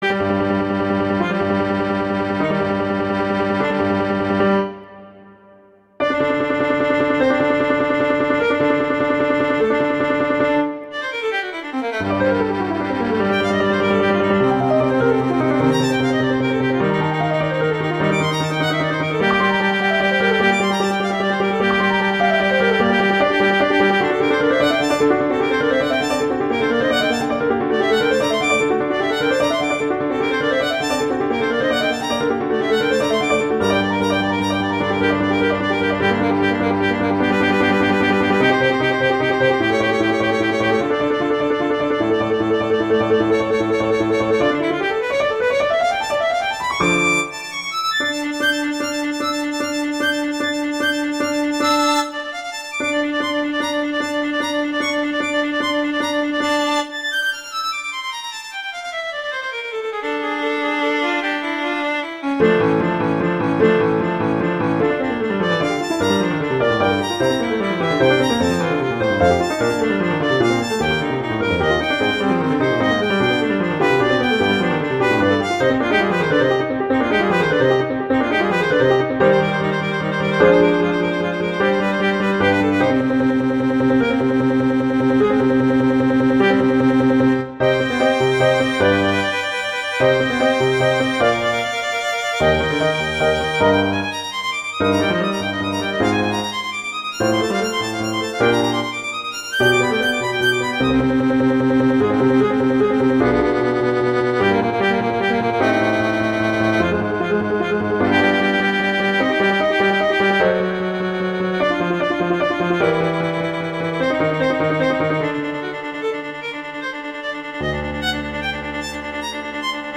violin and piano
classical, concert
G minor